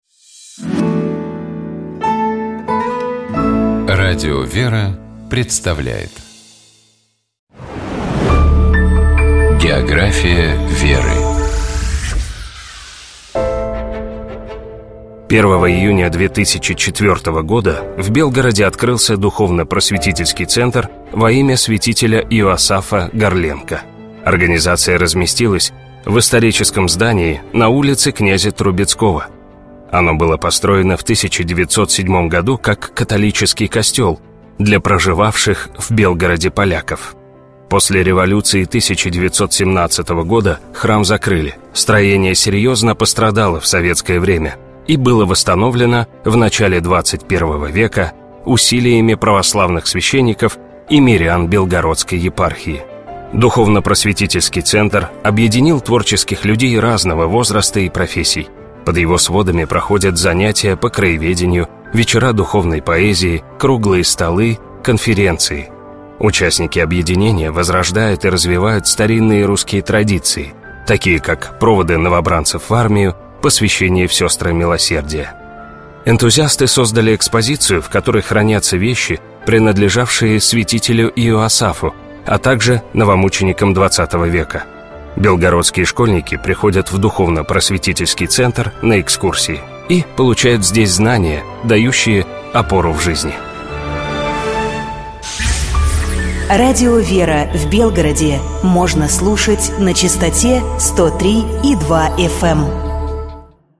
Об этой исторической битве и её героях, чьи имена известны сегодня, пожалуй, каждому, рассказывает мультипликационный фильм «Пересвет и Ослябя». Фрагмент из него прозвучал в начале нашей программы.